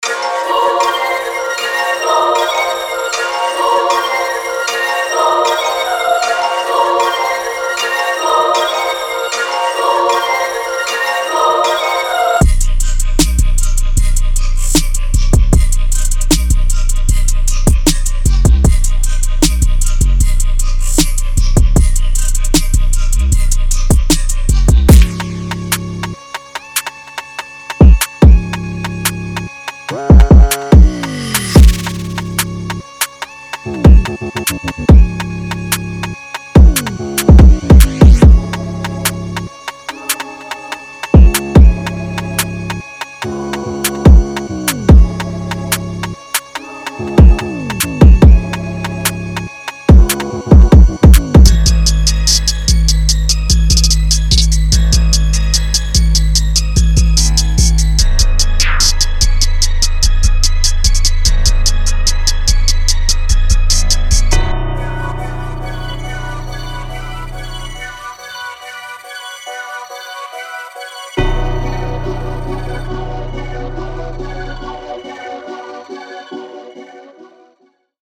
• 10 Melody Loops (HipHop/Trap/Drill)